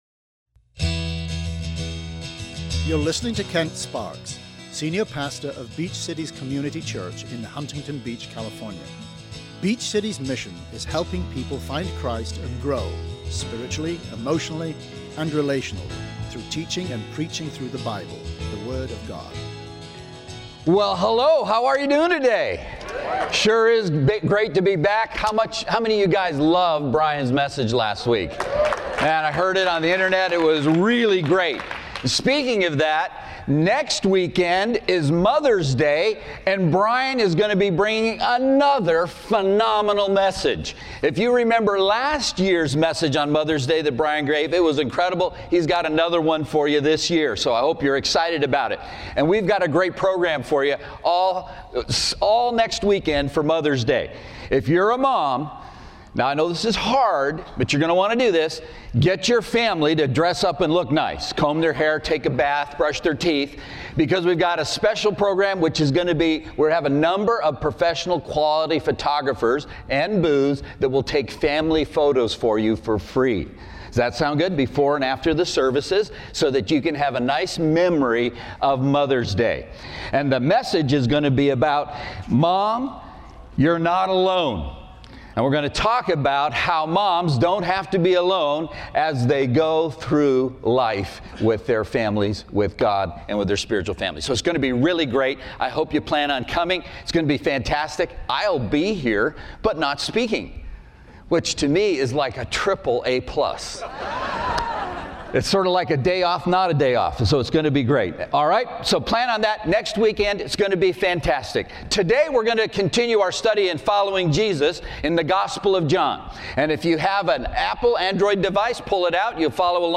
Continue to find out why Jesus is so Unique. SERMON AUDIO: SERMON NOTES: